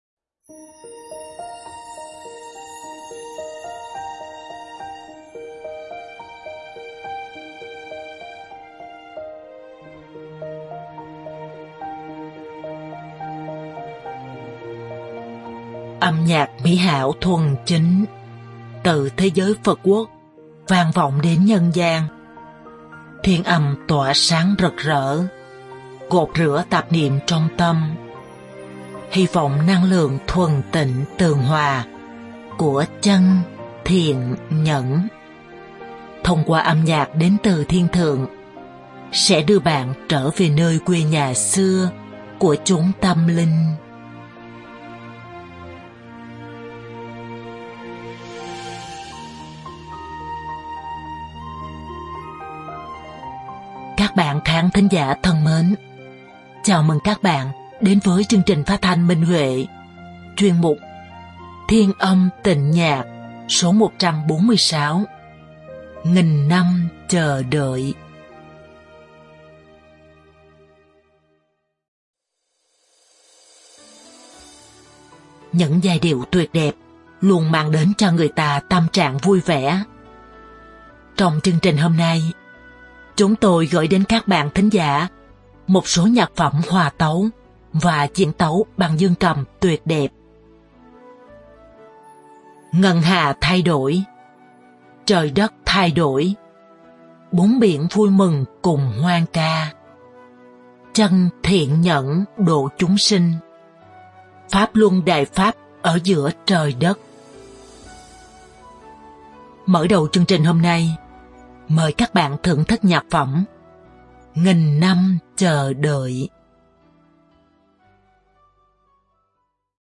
Âm nhạc mỹ hảo thuần chính, từ thế giới Phật quốc vang vọng đến nhân gian, thiên âm tỏa sáng rực rỡ, gột rửa tạp niệm trong tâm, hy vọng năng lượng thuần tịnh